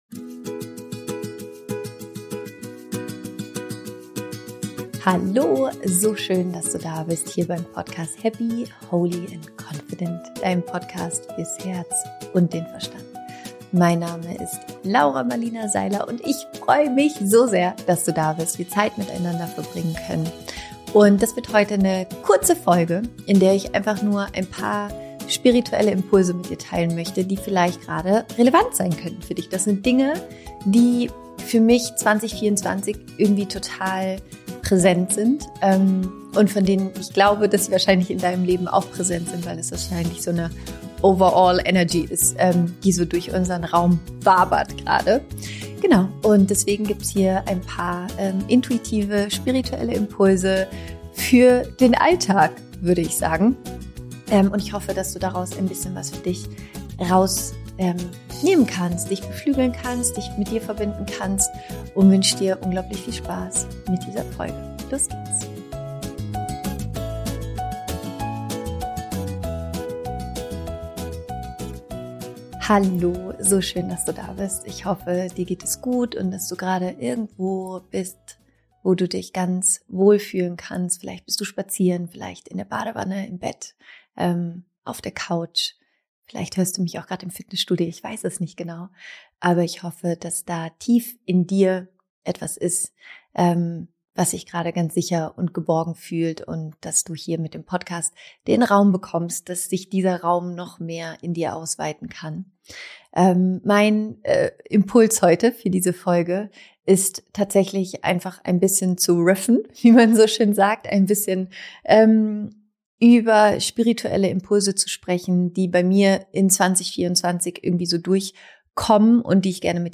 😎🙌 Meine neue Podcastfolge ist vollkommen unvorbereitet und frei aus dem Herzen gesprochen. Es geht um 4 spirituelle Impulse, die aktuell sehr präsent in meinem Jahr sind und die wahrscheinlich gerade auch in deinem Leben präsent sein könnten, denn sie haben mit der Grundenergie zu tun, die gerade durch unseren Raum wabert.